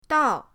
dao4.mp3